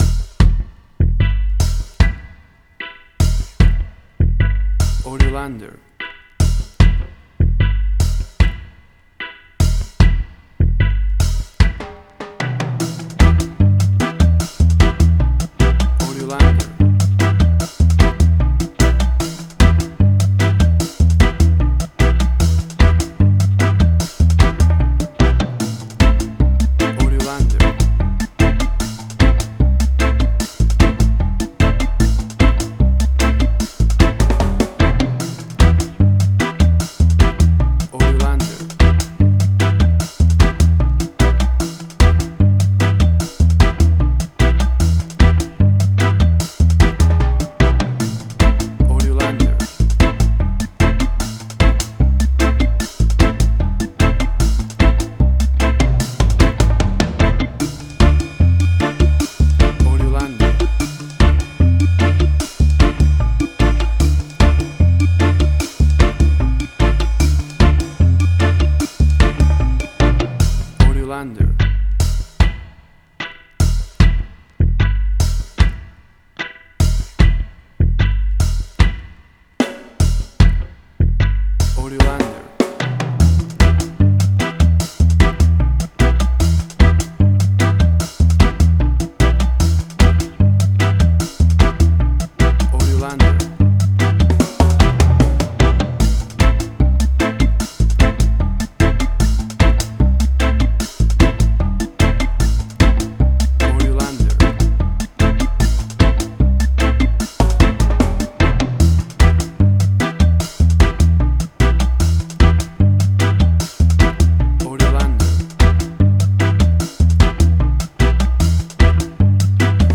Reggae caribbean Dub Roots
Tempo (BPM): 75